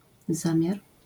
wymowa:
IPA[ˈzãmʲjar], AS[zãmʹi ̯ar], zjawiska fonetyczne: zmięk.nazal.i → j